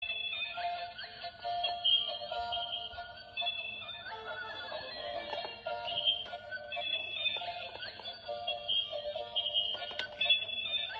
seryi koshka Meme Sound Effect